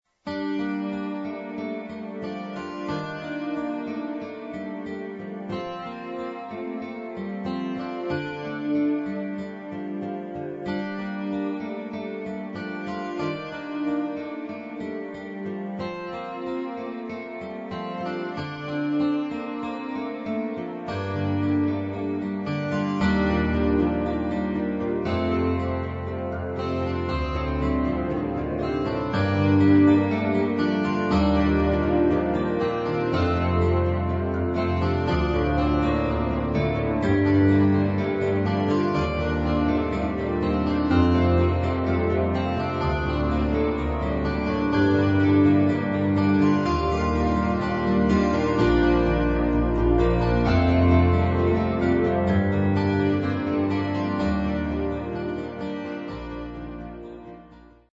Nothing special... just something that I was fooling around with today.
The chords don't sustain long enough to fill the gaps.